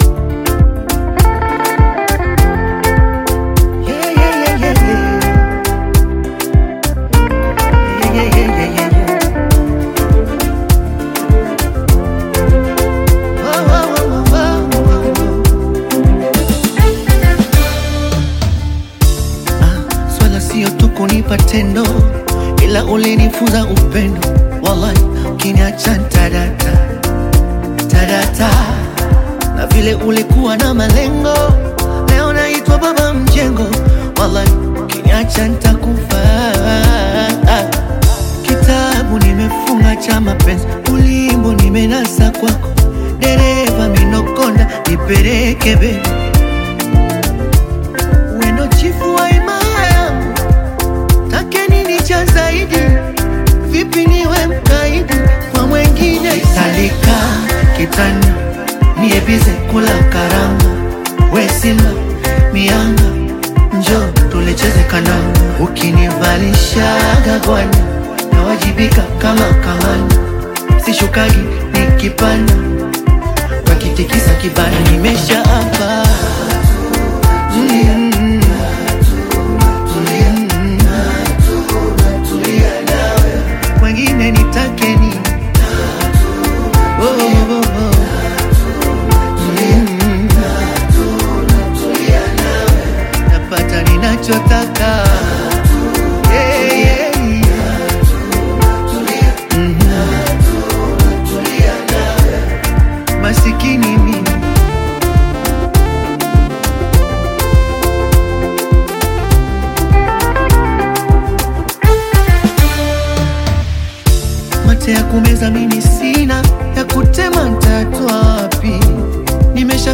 emotionally charged project
With its infectious beat and captivating vocals